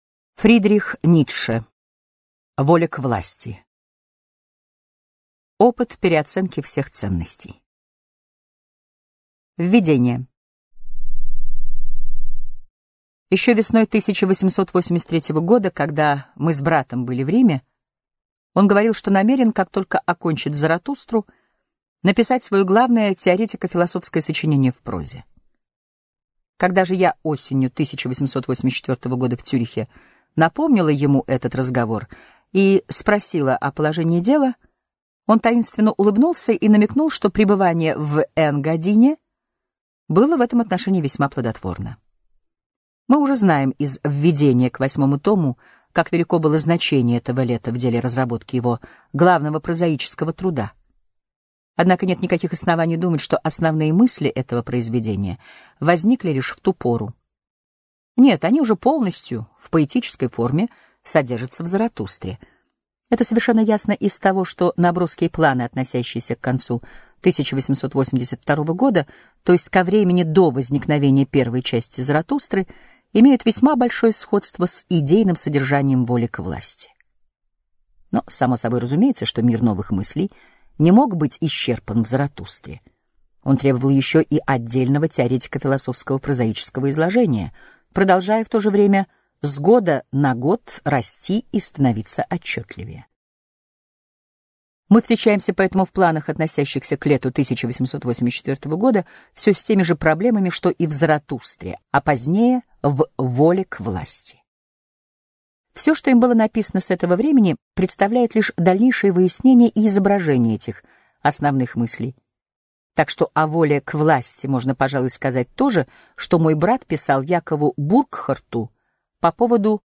Аудиокнига Воля к власти: Опыт переоценки всех ценностей | Библиотека аудиокниг